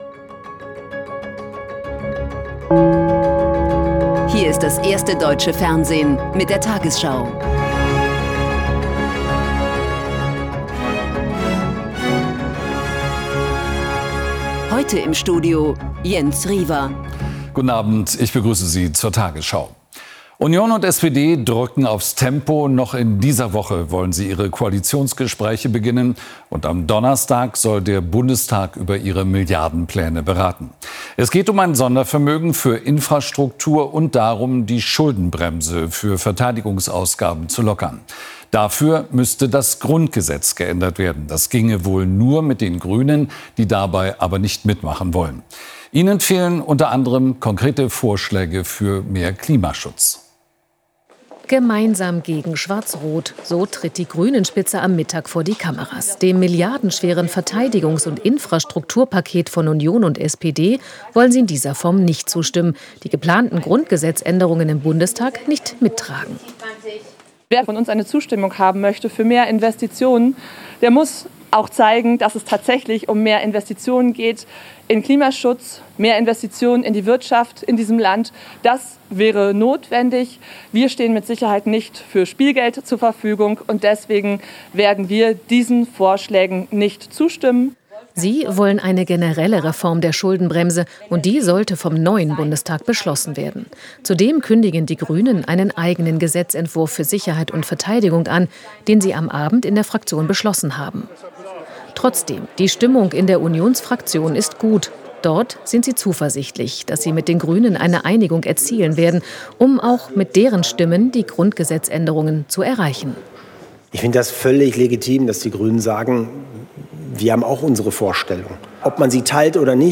Die 20 Uhr Nachrichten von heute zum Nachhören. Hier findet ihr immer, was am Tag aktuell und wichtig ist in den News.